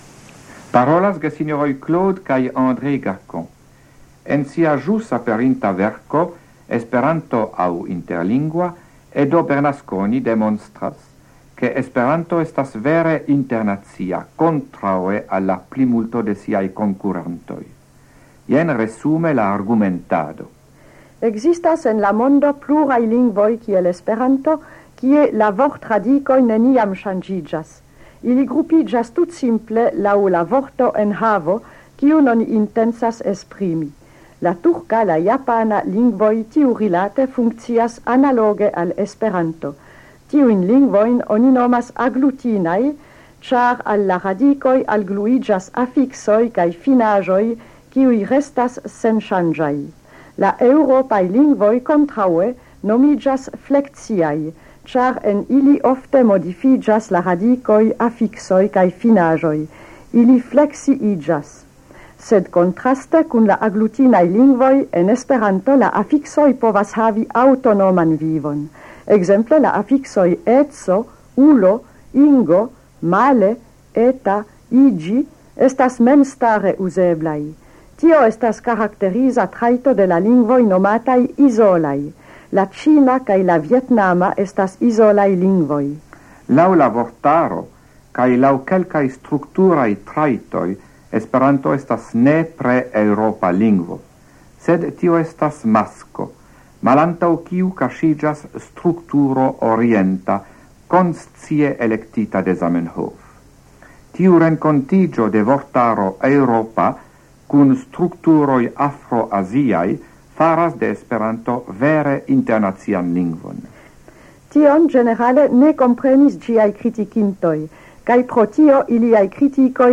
Radioprelegoj en la jaro 1977